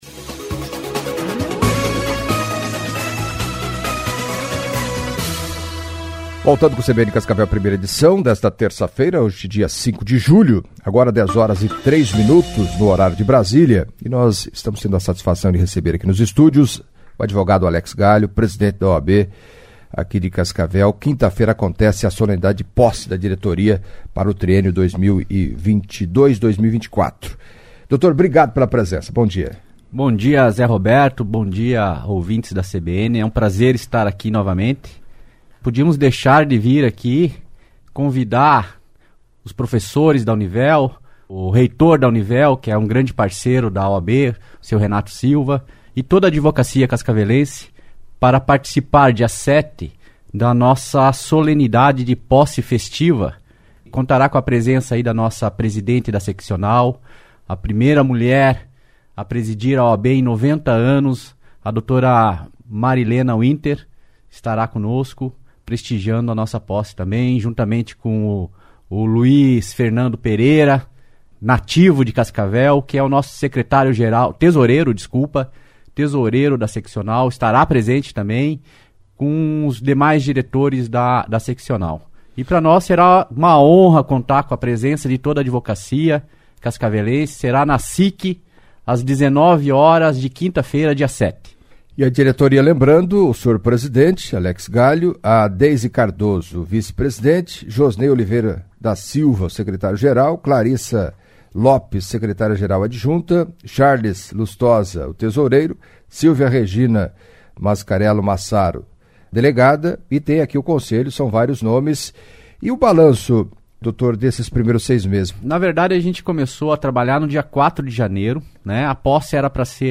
Em entrevista à CBN Cascavel nesta terça-feira